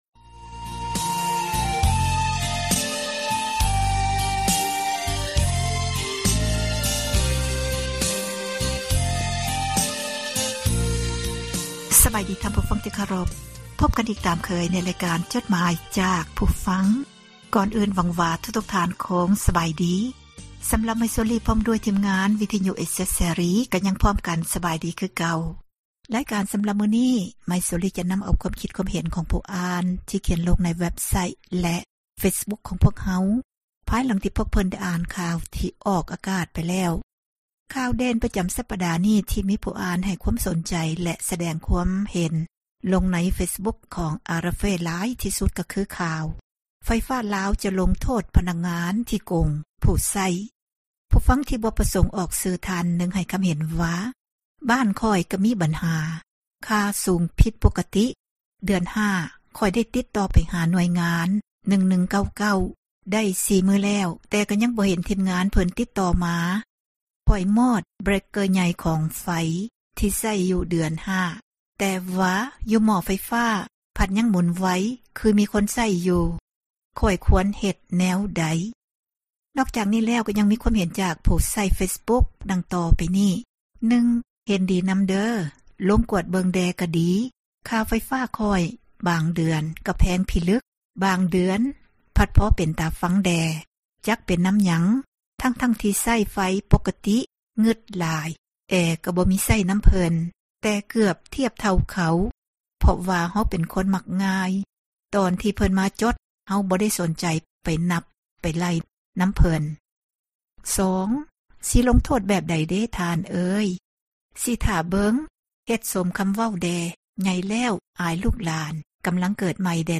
ອ່ານຈົດໝາຍ